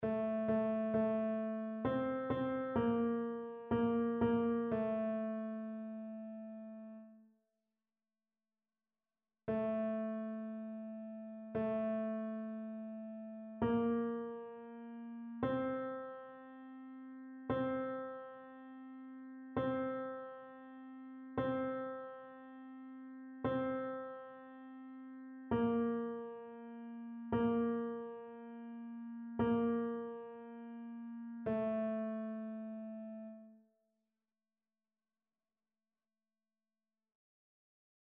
TénorBasse
annee-b-temps-ordinaire-8e-dimanche-psaume-102-tenor.mp3